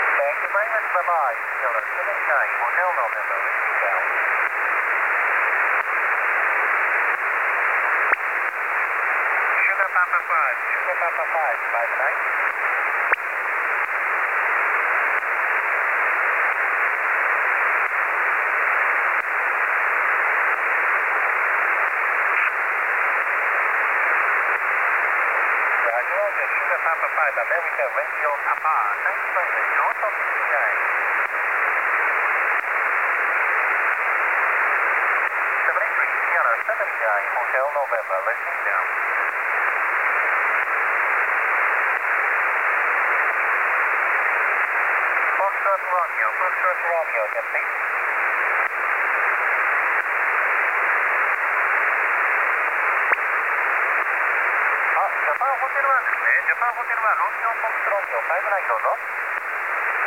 18MHz SSB